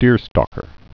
(dîrstôkər)